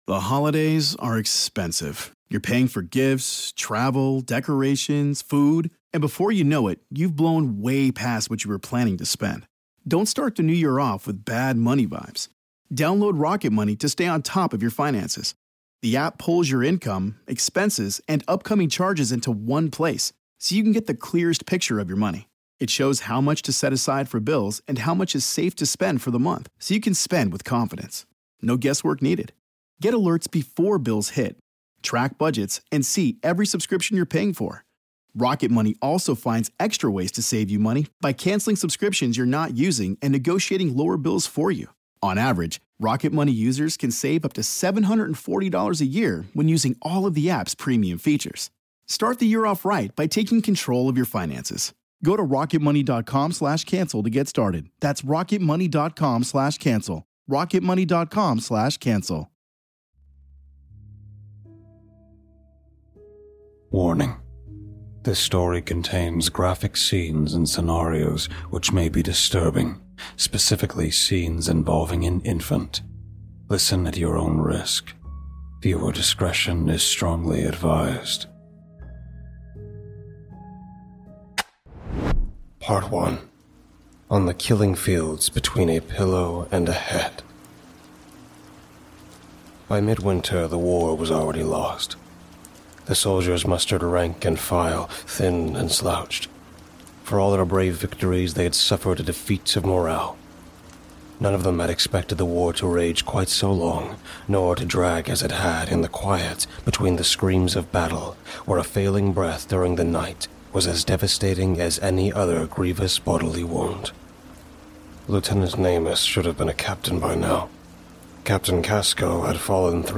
Sad piano Music